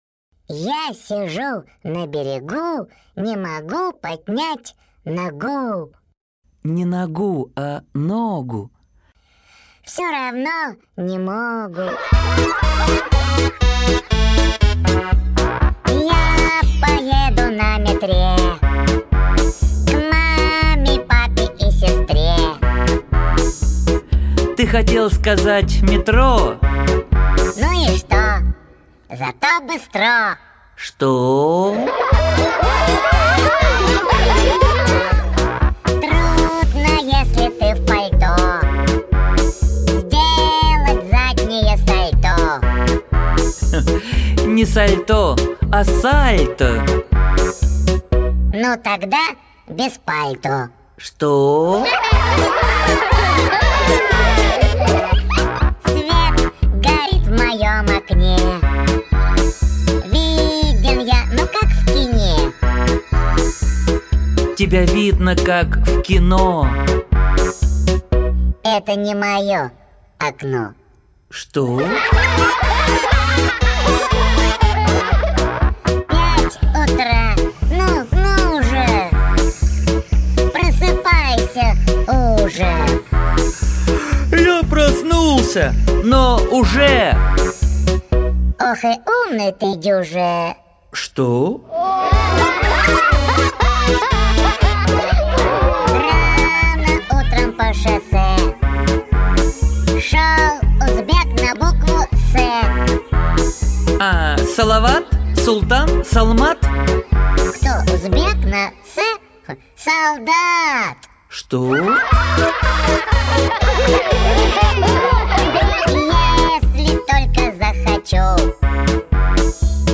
Аудиокнига Музыкальный репетитор | Библиотека аудиокниг